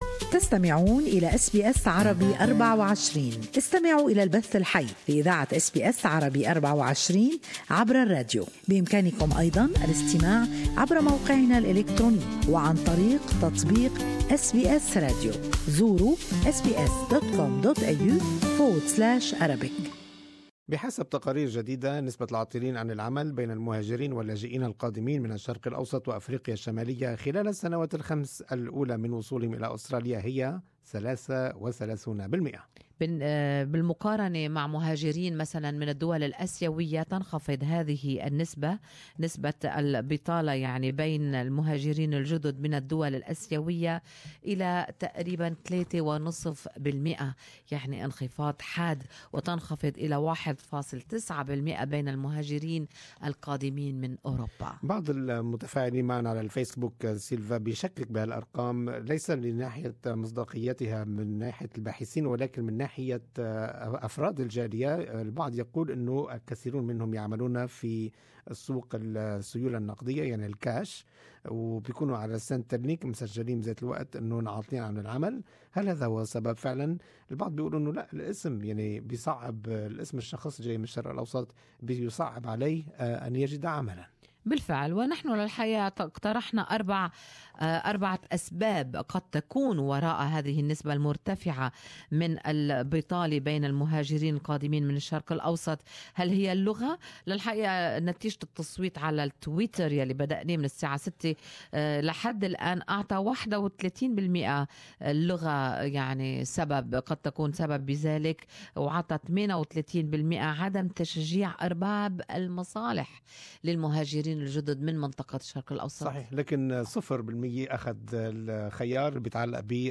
Good Morning Australia listeners share their opinion on the topic.